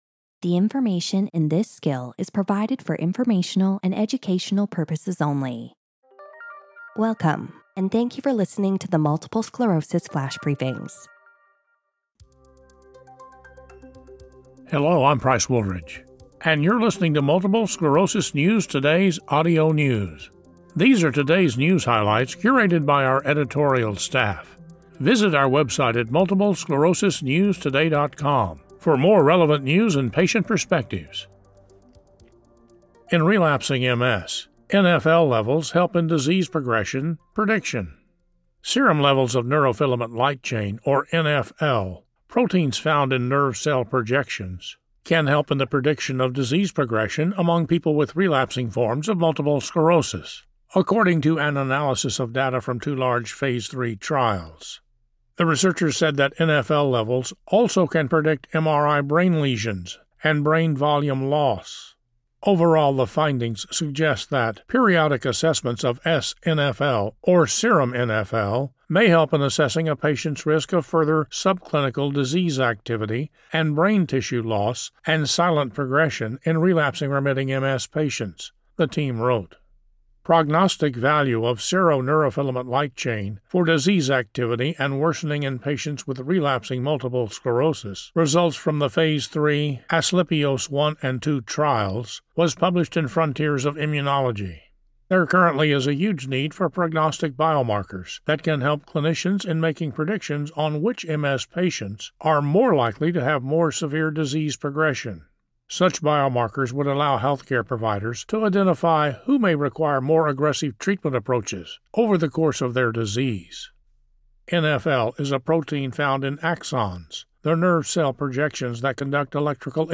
reads a news article about how blood levels of neurofilament light chain (NfL) helped predict disease progression in multiple sclerosis patients in a Phase 3 trial.